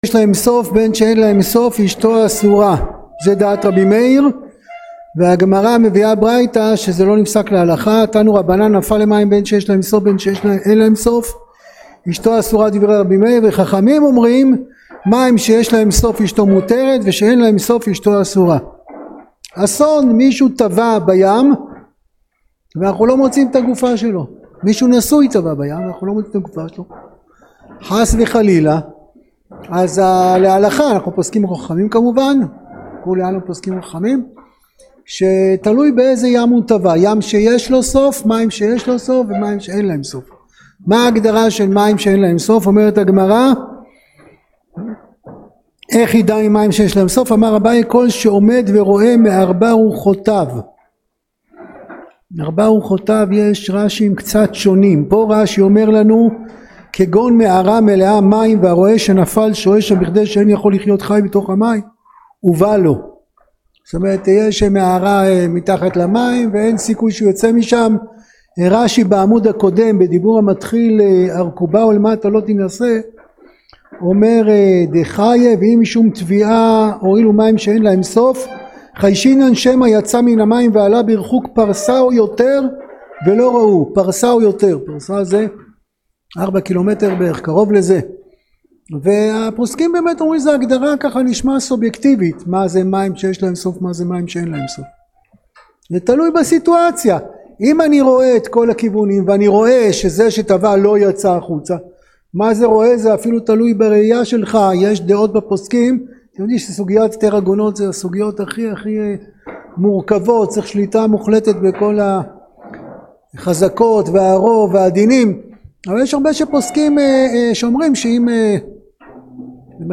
שיעור-כללי-כח-תמוז.mp3